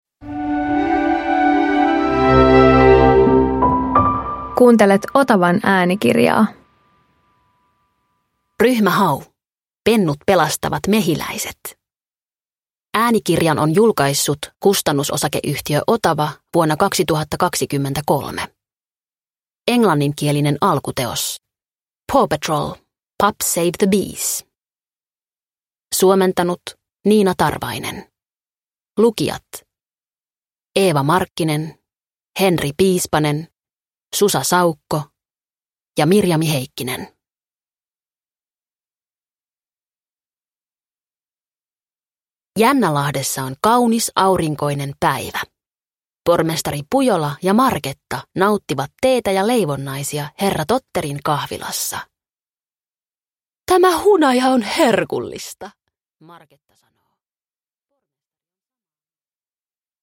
Ryhmä Hau - Pennut pelastavat mehiläiset – Ljudbok – Laddas ner